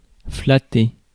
Ääntäminen
France: IPA: [fla.te]